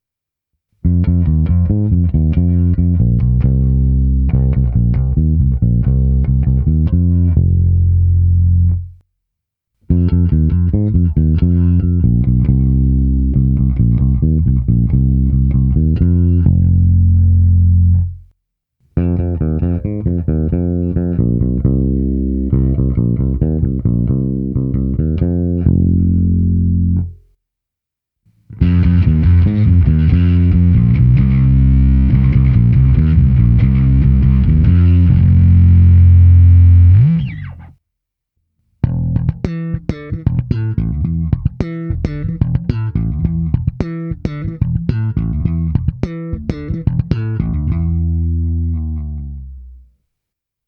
Abych alespoň trochu simuloval, jak hraje basa přes aparát, je tu opět nahrávka přes Darkglass Alpha Omega Ultra se zapnutou simulací aparátu a kompresor TC Electronic SpectraComp, nejdříve prsty v pořadí krkový snímač, oba snímače, kobylkový snímač, pak oba snímače se zkreslením a nakonec slap na oba snímače.
Nahrávka přes Darkglass